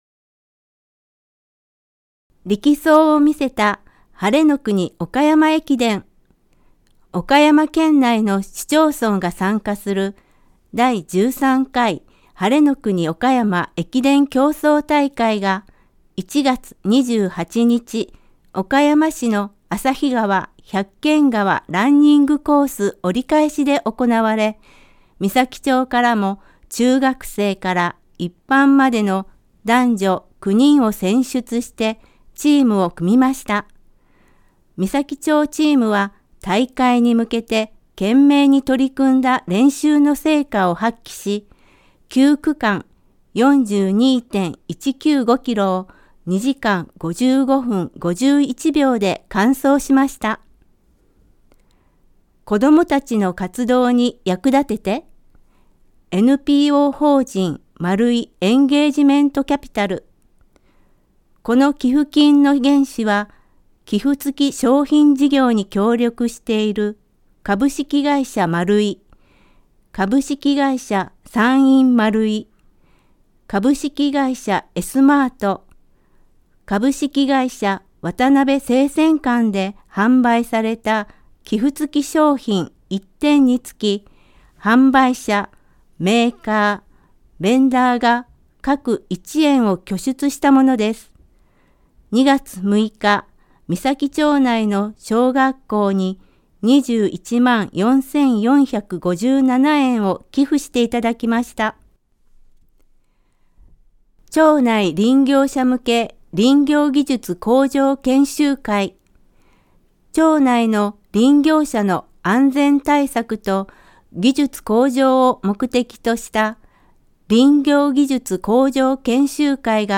声の広報（広報紙の一部を読み上げています）